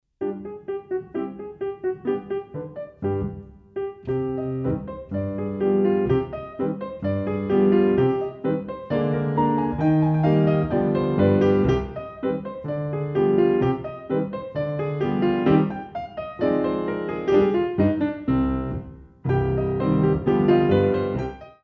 Echappés